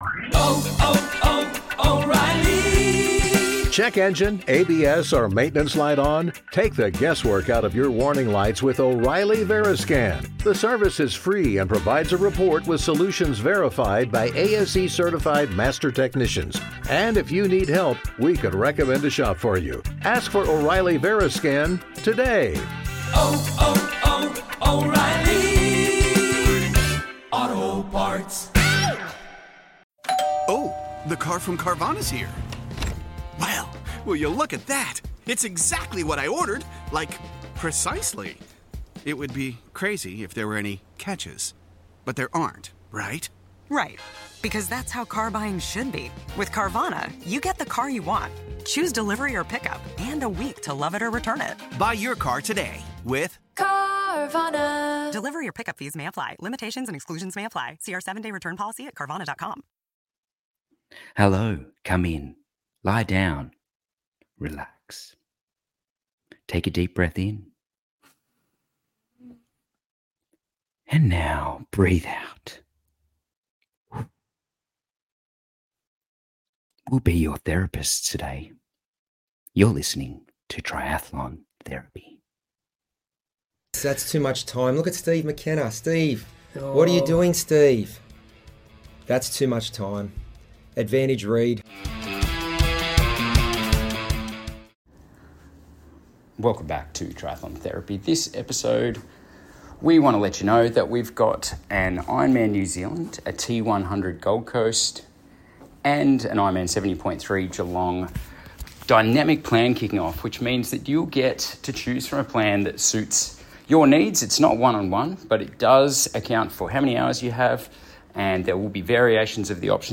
You know him, you love him and you will be very familiar with that raspy voice that so many of us love calling us across the finish line.